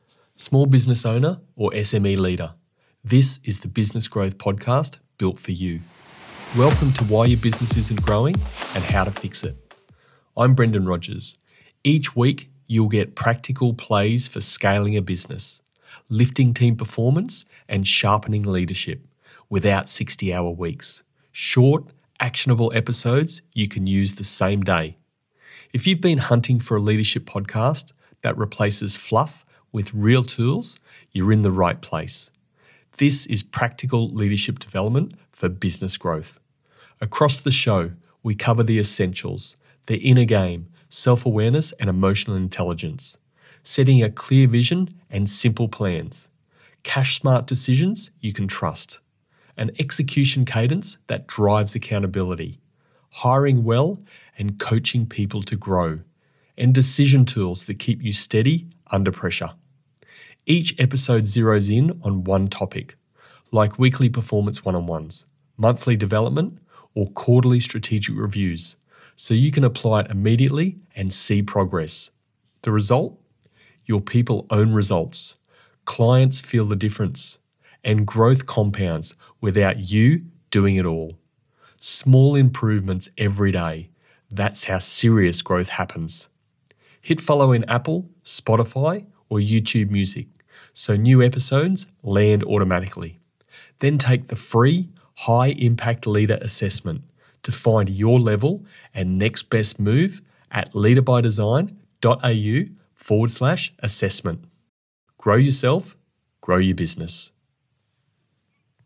Start Here: Why Your Business Isn’t Growing (Trailer)